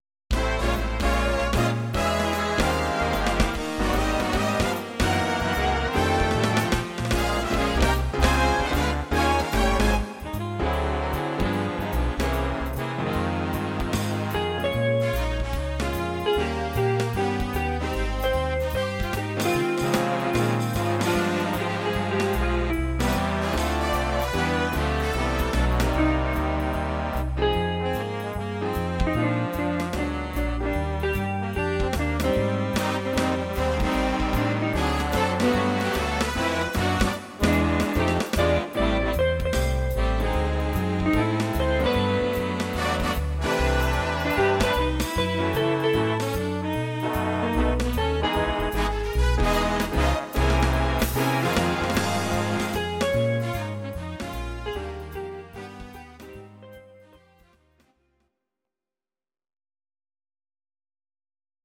These are MP3 versions of our MIDI file catalogue.
Please note: no vocals and no karaoke included.
Big Band Version